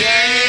Hey " of Rat Poison is from Nirvana - Smells Like Teen Spirit (backwards).